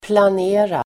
Uttal: [plan'e:rar]